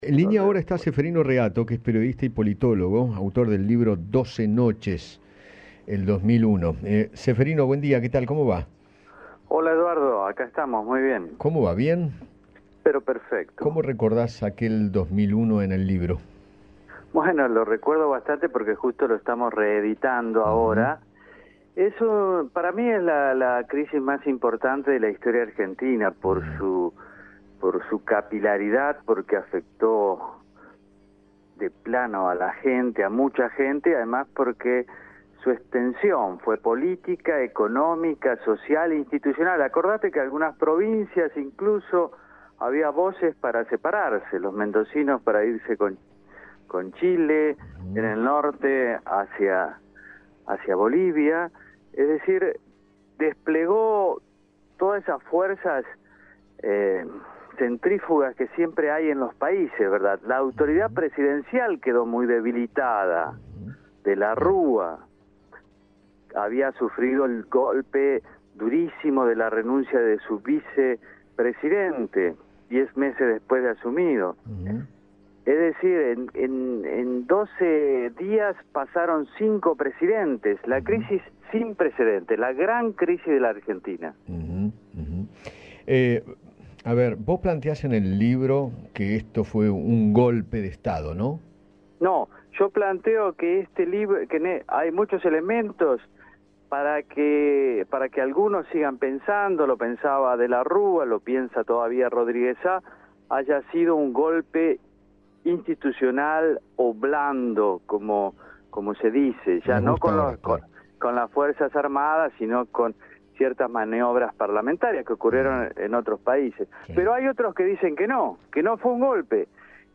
Ceferino Reato, periodista y politólogo, habló con Eduardo Feinmann sobre la crisis del 2001, a 20 años del corralito, y recordó que “su extensión fue política, económica, social e institucional”.